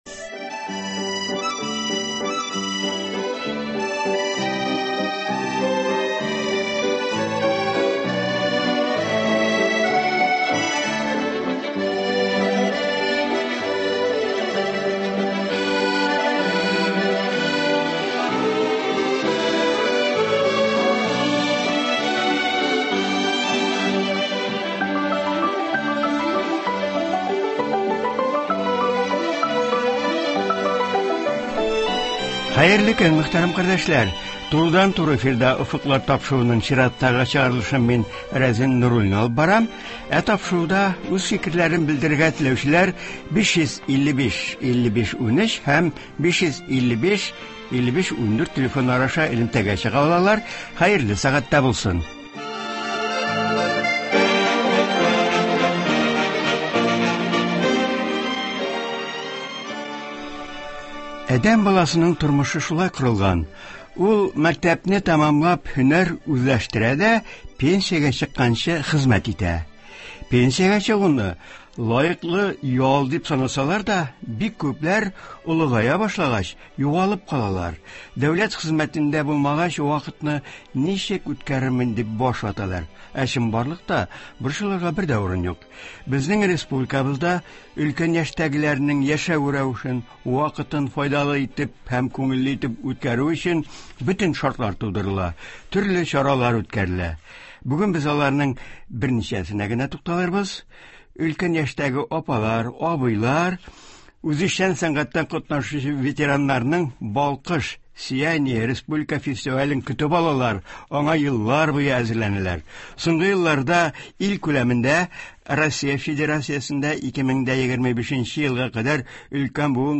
тапшыруда шулай ук фестивальдә катнашкан ветераннар башкаруында җырлар яңгырый.